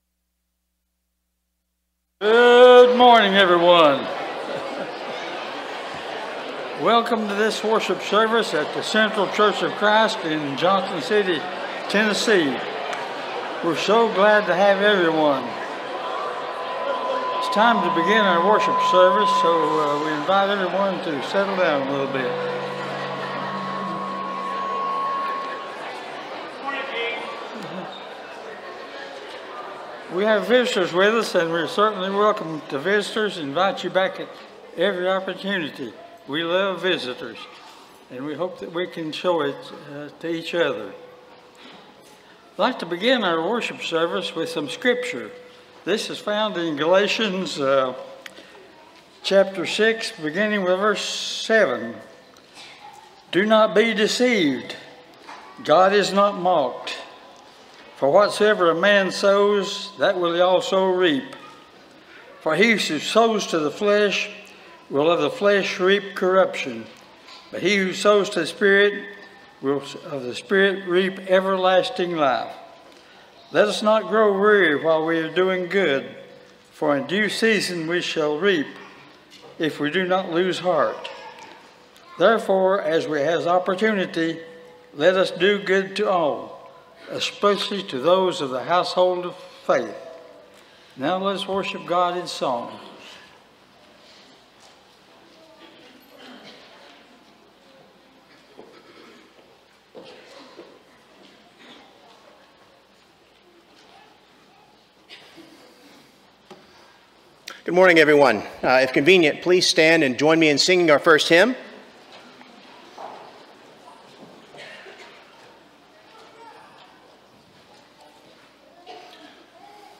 Proverbs 16:18 (English Standard Version) Series: Sunday AM Service